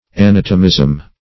Anatomism \A*nat"o*mism\, n. [Cf. F. anatomisme.]